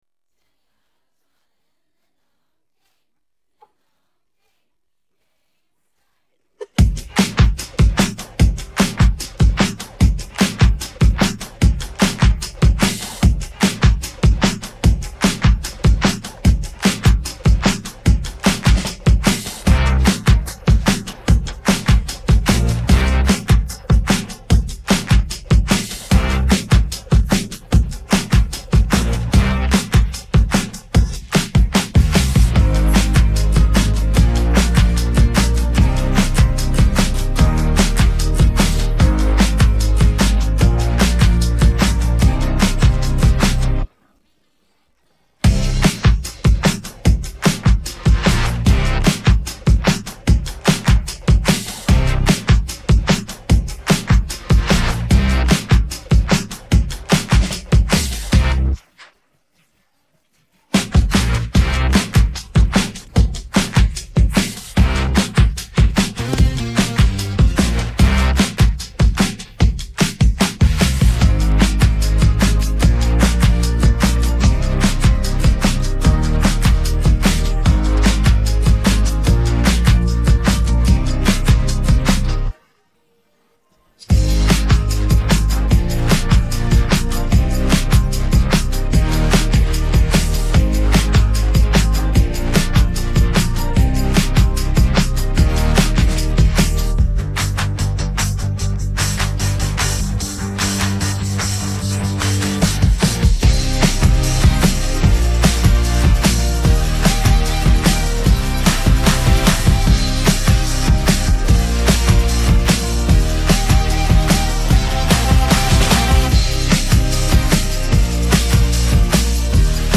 Musique de fond